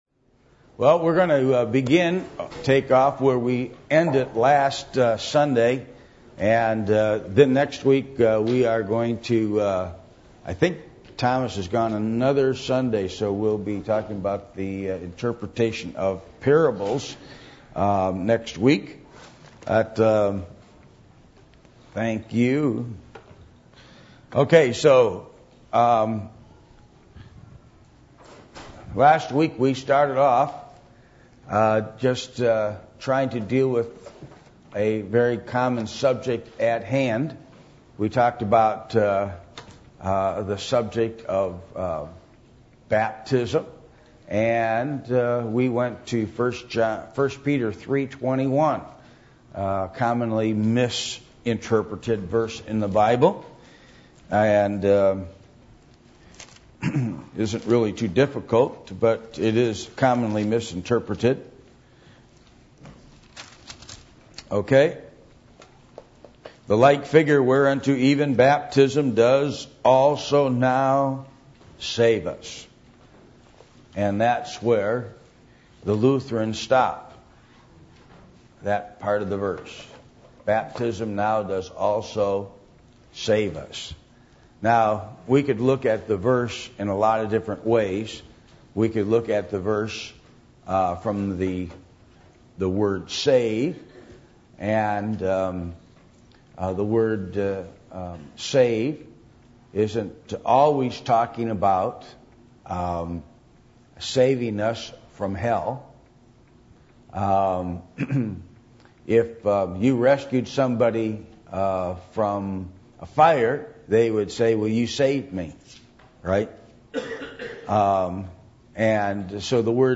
Service Type: Adult Sunday School